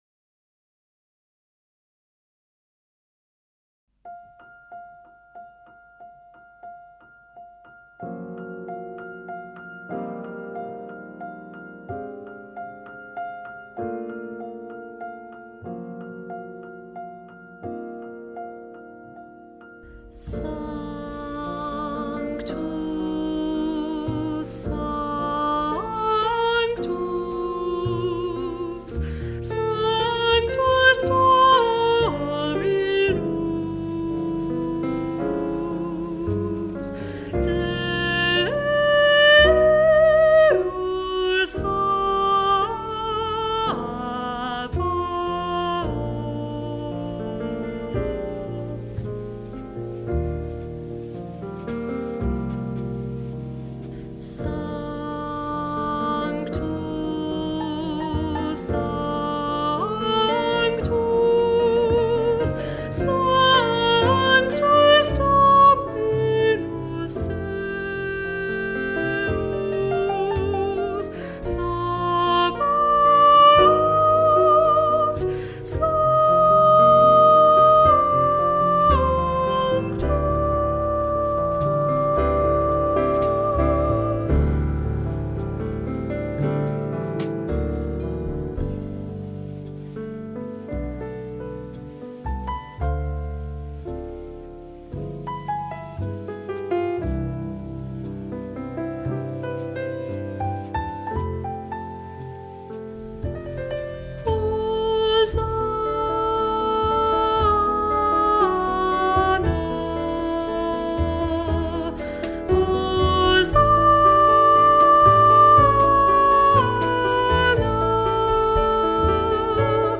Sanctus-Soprano.mp3